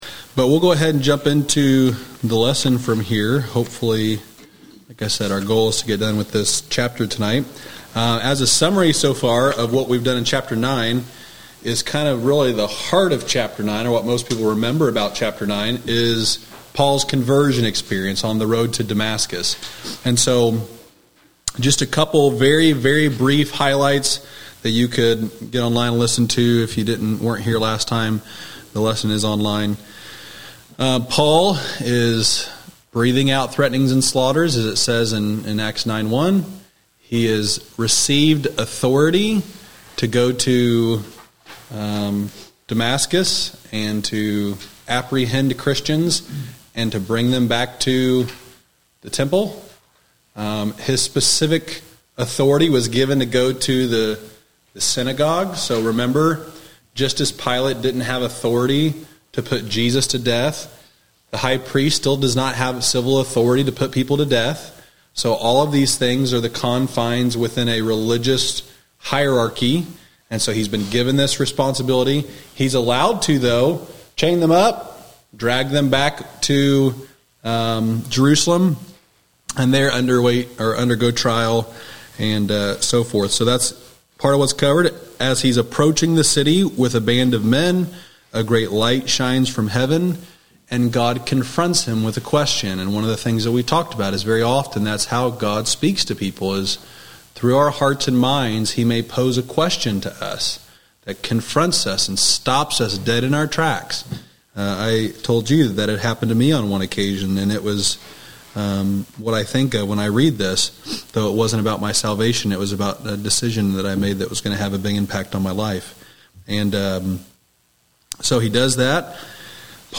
Wednesday night lesson from November 29, 2023 at Old Union Missionary Baptist Church in Bowling Green, Kentucky.